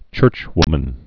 (chûrchwmən)